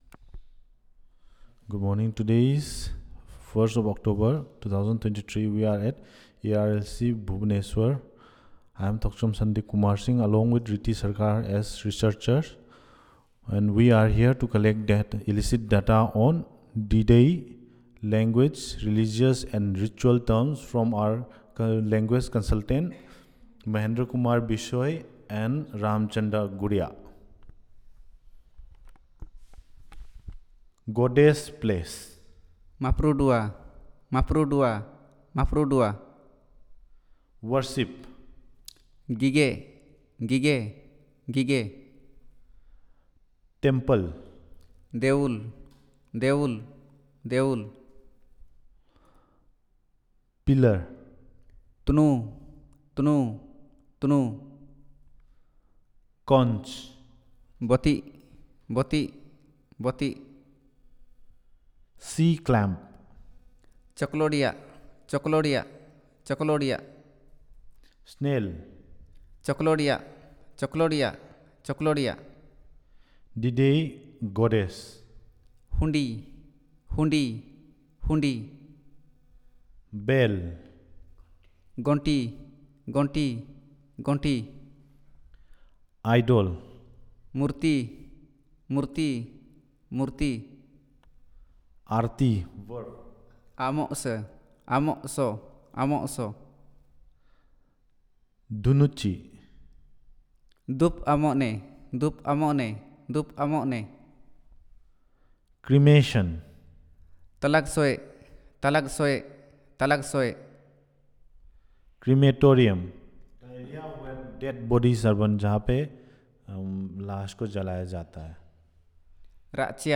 Elicitation of words on religion and ritual terms